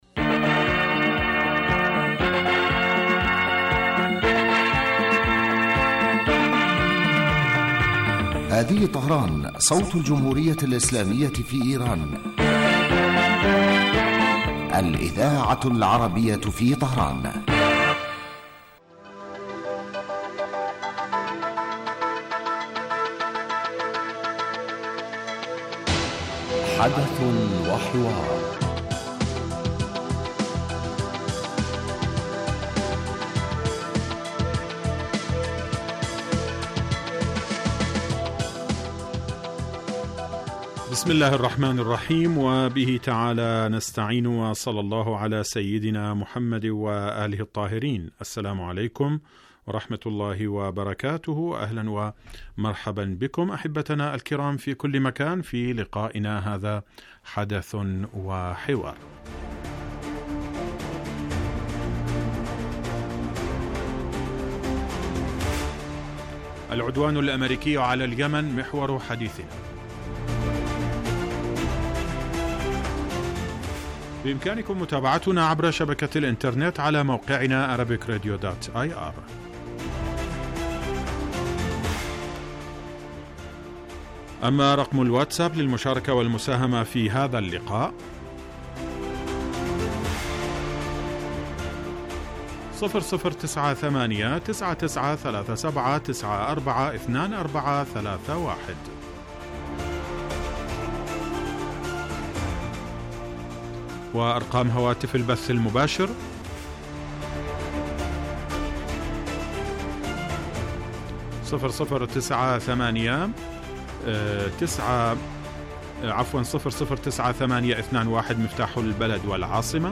يبدأ البرنامج بمقدمة يتناول فيها المقدم الموضوع ثم يطرحه للنقاش من خلال تساؤلات يوجهها للخبير السياسي الضيف في الاستوديو . ثم يتم تلقي مداخلات من المستمعين هاتفيا حول الرؤى التي يطرحها ضيف الاستوديو وخبير آخر يتم استقباله عبر الهاتف ويتناول الموضوع بصورة تحليلية.